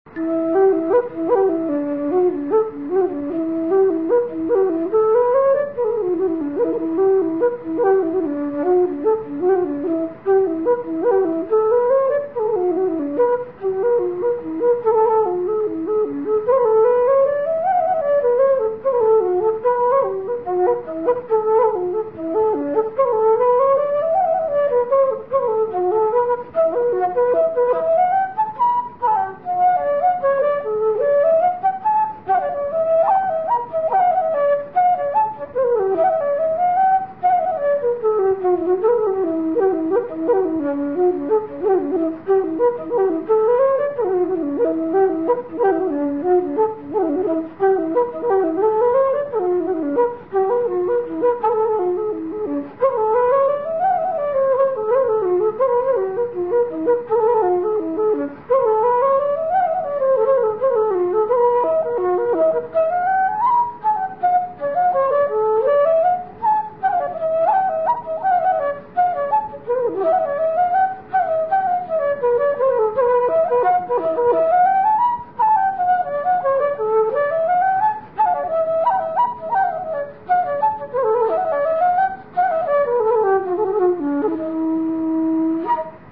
Here are a few short folk melodies I have composed.
A lively tune